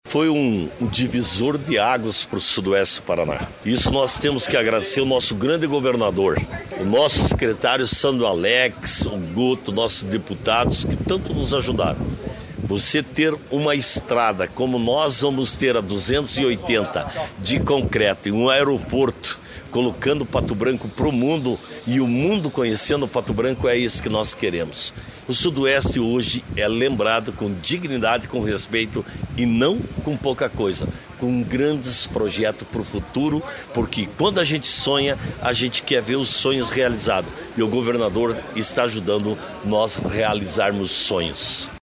Sonora do prefeito de Pato Branco, Robson Cantu, sobre a revitalização da PRC-280 em concreto de Palmas a Pato Branco | Governo do Estado do Paraná